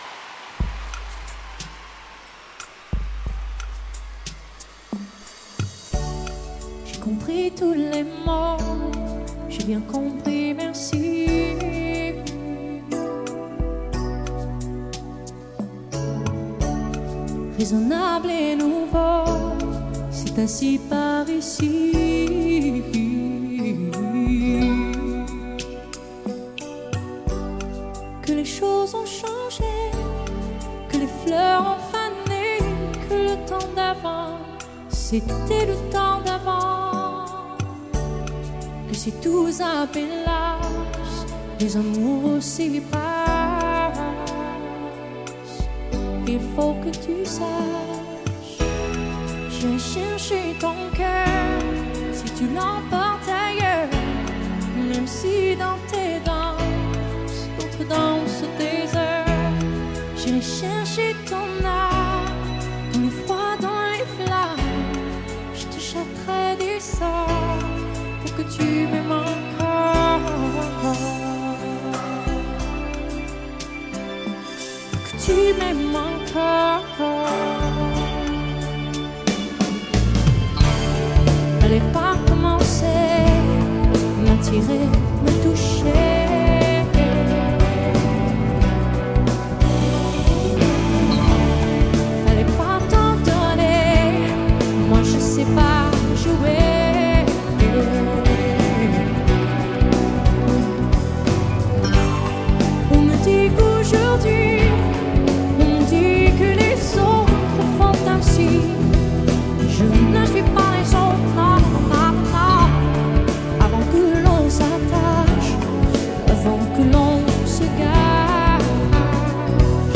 而她现场演那种爆发力和穿透力更是让人留下难以磨灭的印象，此辑便是最好的佐证。
此张以法语作品为主的现场演唱会实况精选专辑，所收作品皆是现场收音于1999年所举办的世界巡回演唱会。